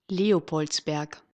The Leopoldsberg (Austrian German pronunciation: [ˈleːopɔldsˌbɛrɡ]